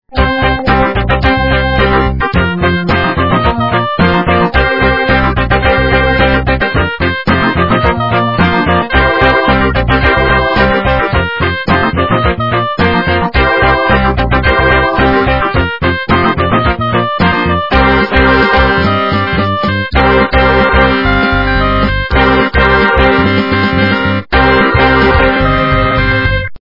- рок, металл